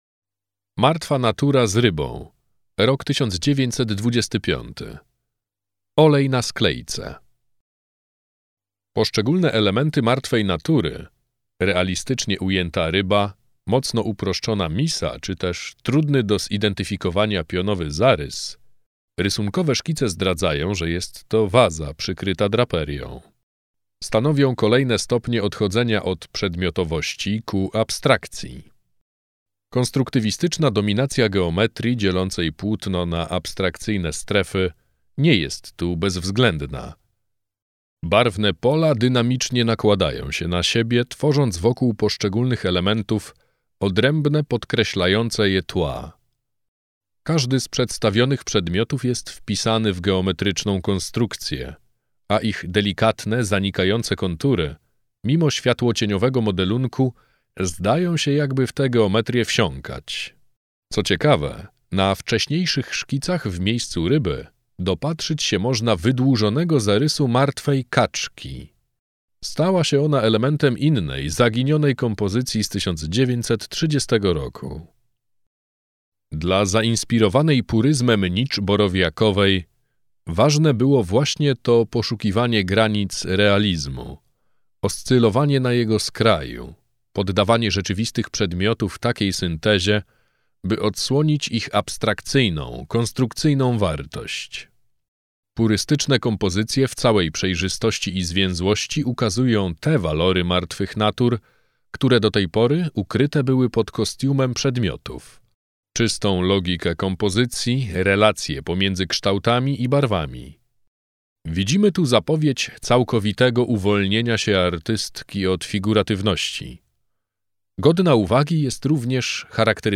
4.-Maria-Nicz-Borowiakowa-Martwa-natura-z-ryba-lektor.mp3